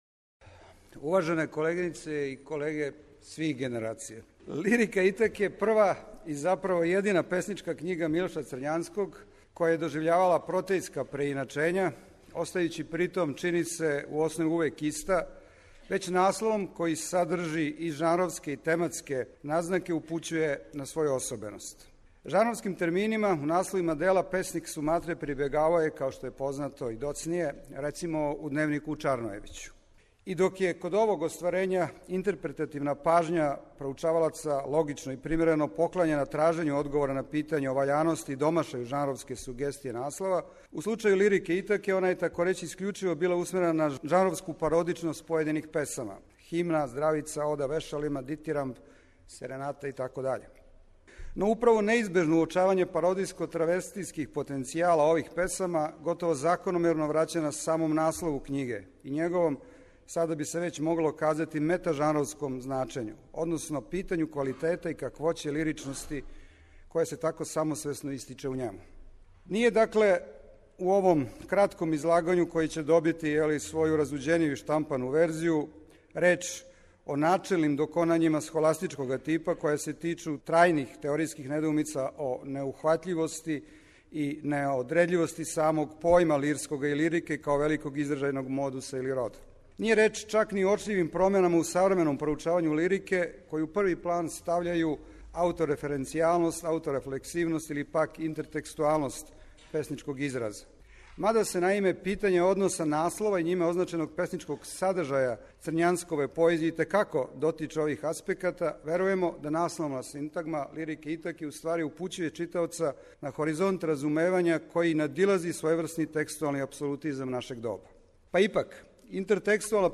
U ciklusu MILOŠ CRNjANSKI: POEZIJA I KOMENTARI, u sredu 5. marta, moći ćete da pratite snimke izlaganja sa istoimenog naučnog skupa koji je krajem prošle godine održan na Filološkom fakultetu u Beogradu.